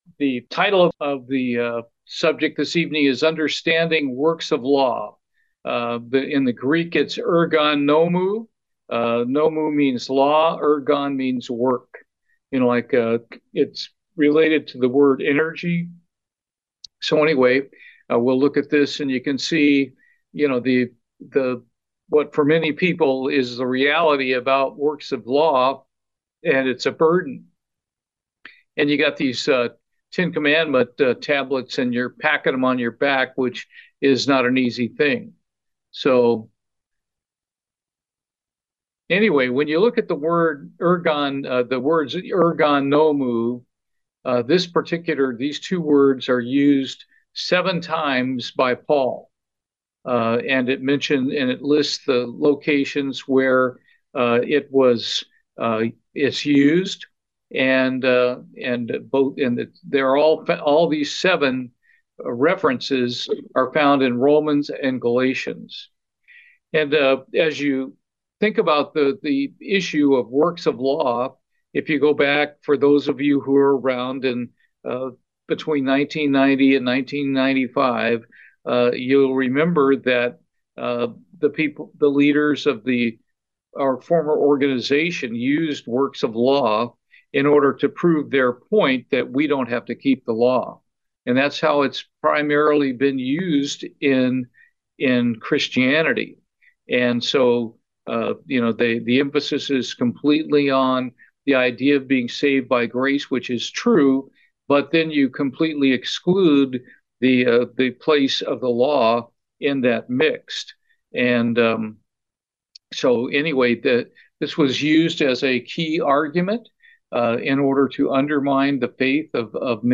Bible study, \Works of Law